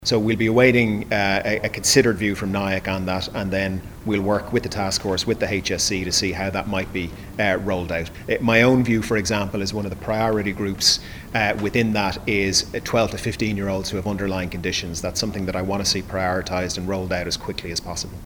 Minister Stephen Donnelly says one option is to make it part of a school vaccination programme: